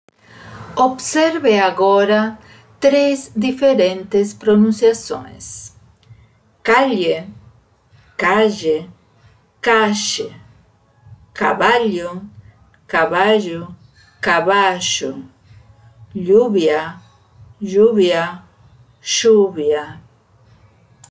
O som característico da pronúncia de “LH” acompanhado de uma vogal em português, por exemplo na palavra “folha”, corresponde ao som do “LL” em espanhol.
Vale ressaltar que dependendo do sotaque de espanhol de determinado país, a pronúncia pode ser muito semelhante à do português, mas também pode soar um pouco diferente.